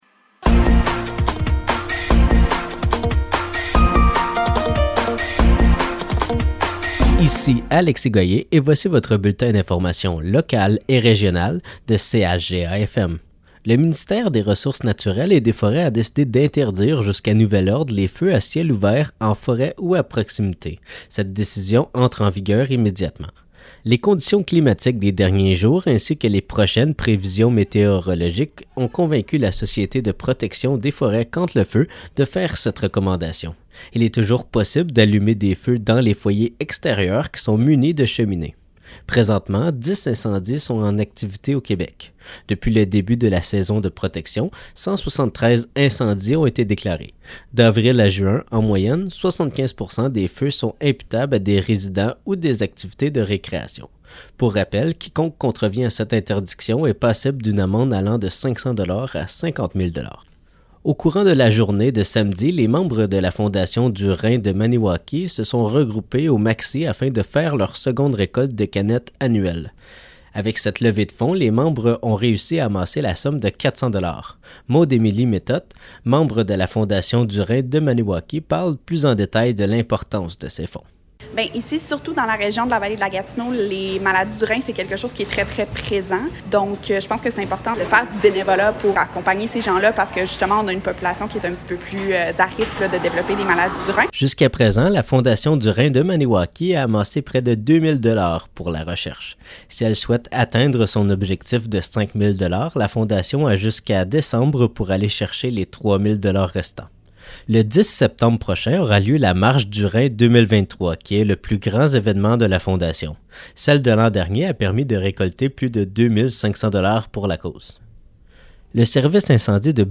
Nouvelles locales - 29 mai 2023 - 12 h